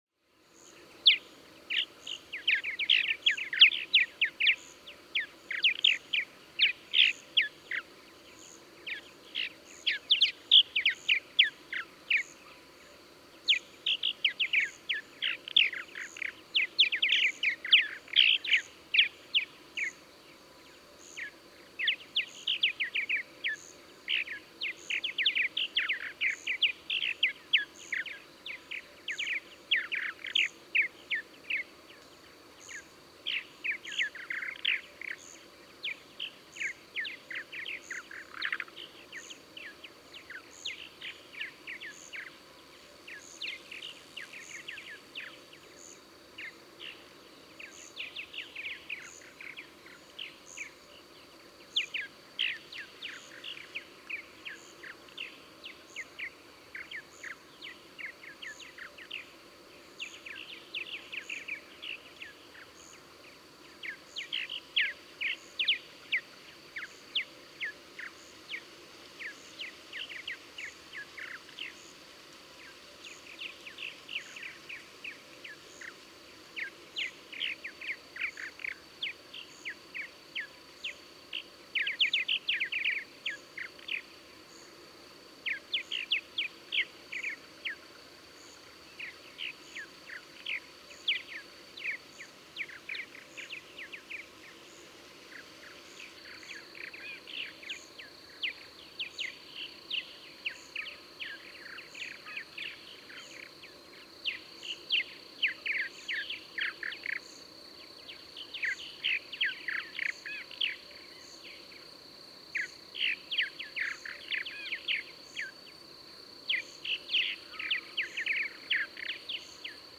Purple martin
Subchapter: Night singing
In the dark, two to three hours before sunrise, older males sing high overhead, apparently in an attempt to attract yearling birds to the nesting colony.
Ferne Clyffe State Park, Goreville, Illinois.
515_Purple_Martin.mp3